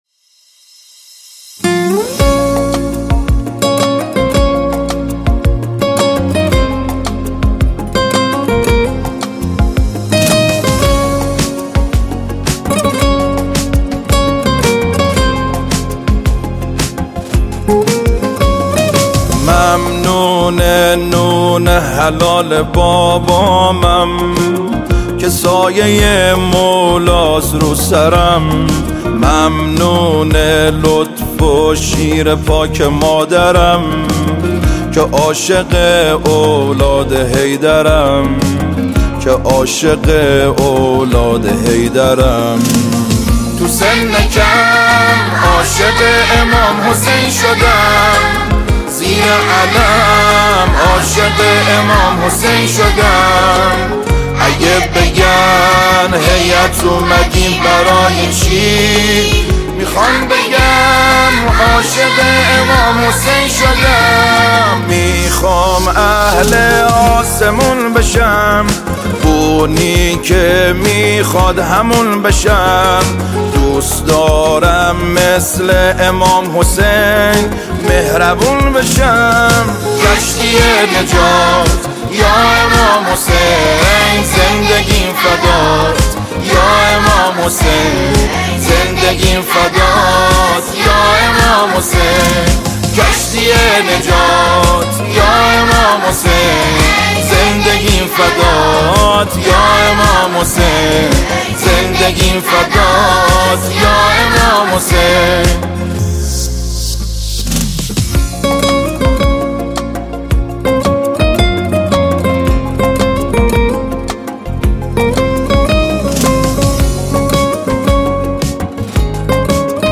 سرودهای ولادت امام حسین علیه السلام